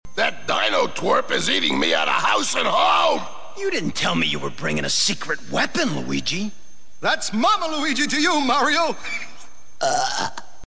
groan.mp3